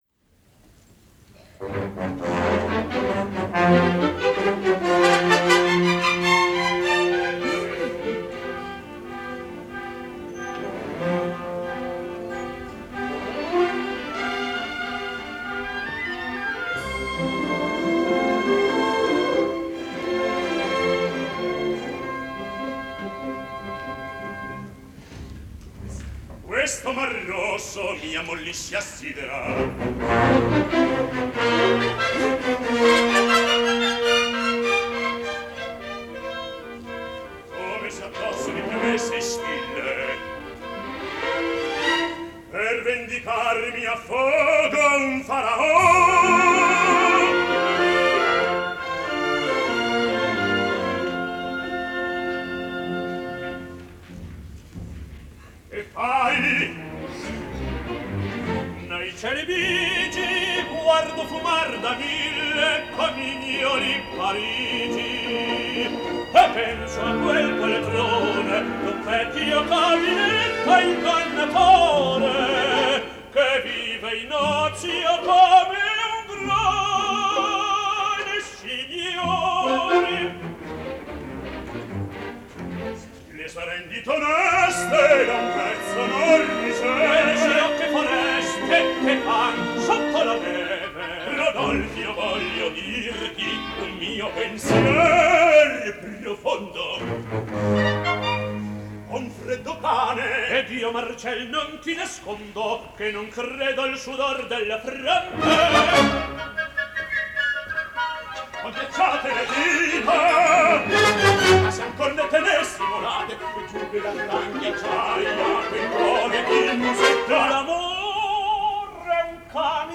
Live performance recorded February 15, 1958
RODOLFO - Carlo Bergonzi
MIMI - Licia Albanese
Orchestra and Chorus
THOMAS SCHIPPERS